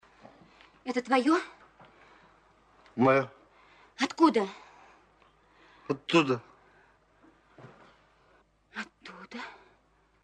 Звуки цитат из фильмов
Это твоё? Откуда? Оттуда (фраза из Бриллиантовой руки)